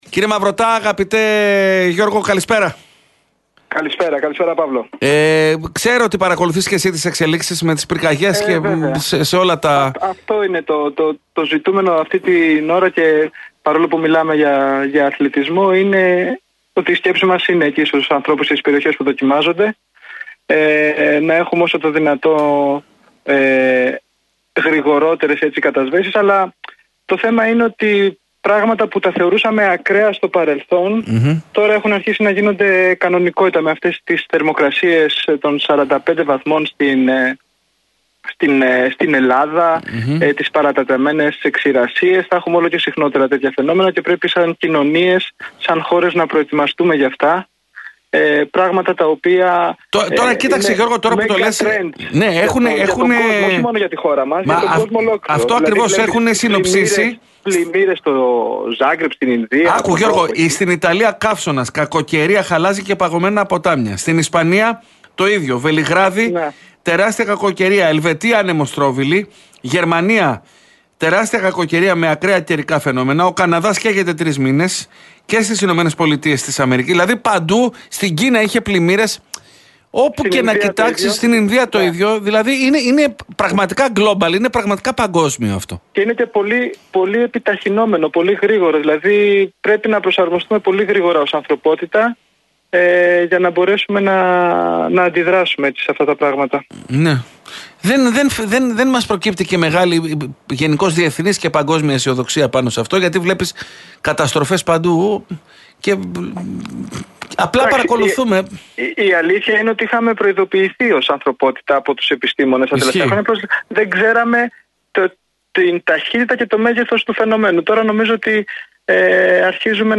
Αισιόδοξος για την πορεία των εθνικών μας ομάδων πόλο ανδρών και γυναικών στο Παγκόσμιο Πρωτάθλημα, όπου ήδη έχουν προκριθεί στα προημιτελικά, δηλώνει στον «Realfm 97,8» ο Γενικός Γραμματέας Αθλητισμού Γιώργος Μαυρωτάς.